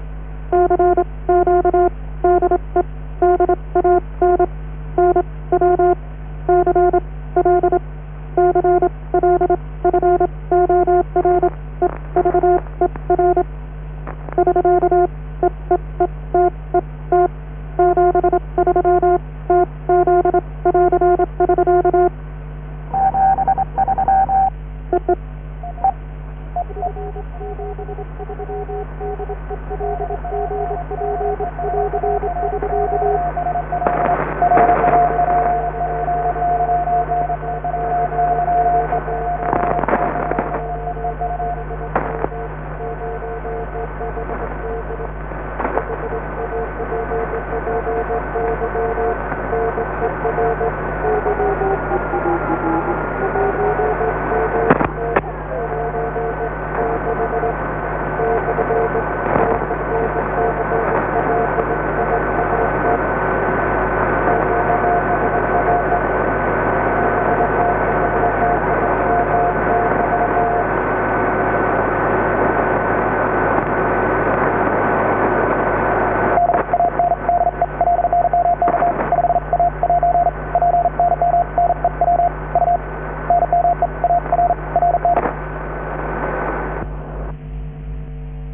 Last Morse Signals of DAN on 500 kHz